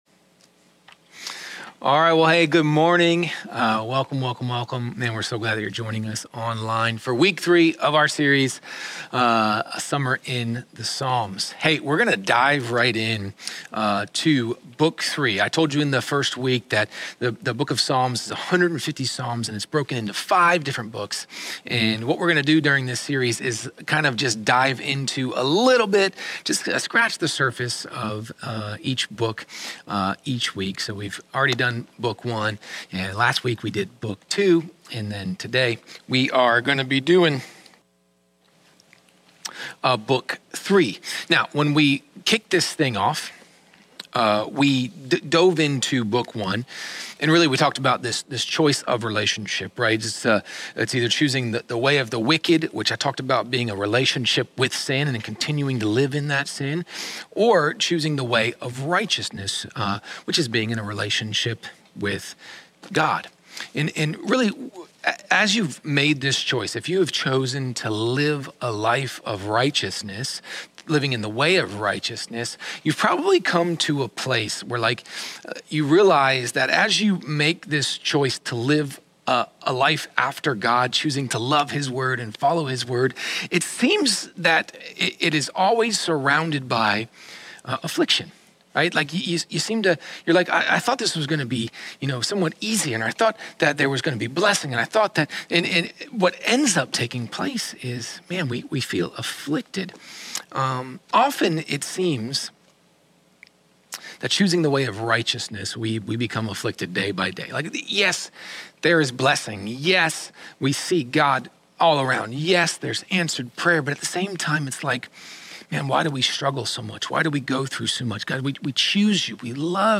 Sermons | Kairos Church
Book 3: Turning from Relationship - Online Service